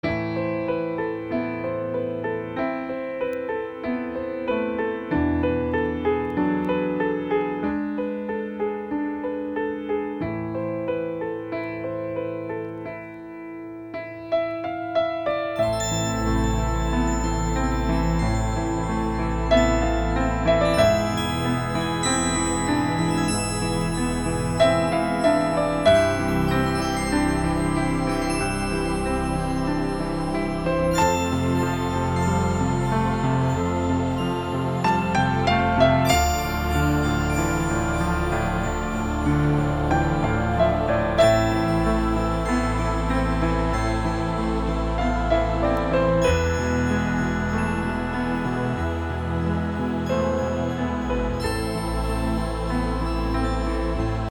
спокойные
без слов
инструментальные
пианино
колокольчики
New Age
джаз